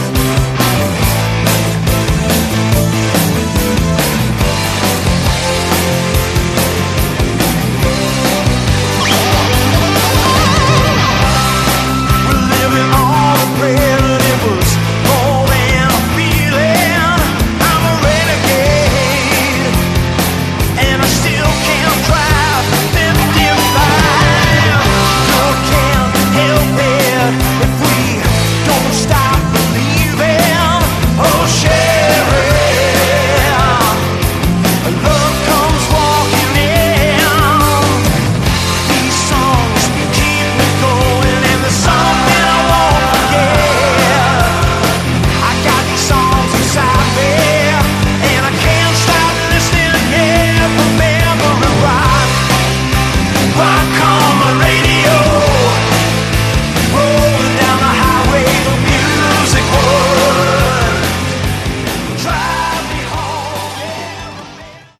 Category: AOR
drums, vocals
guitars, vocals
lead vocals
bass
guitar, vocals, keys